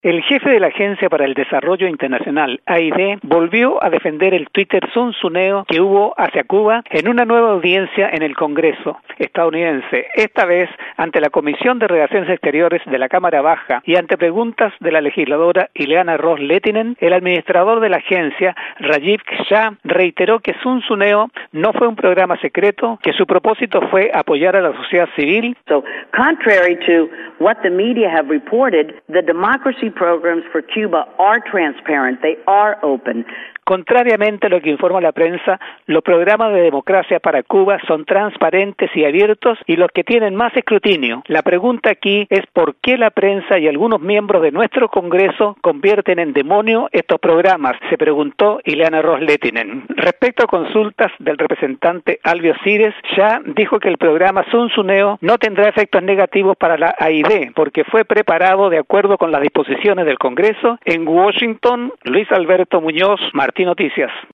El jefe de la Agencia de Estados Unidos para el Desarrollo Internacional defendió nuevamente el proyecto Zunzuneo en una audiencia en el Congreso. Desde Washington